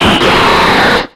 Cri de Sharpedo dans Pokémon X et Y.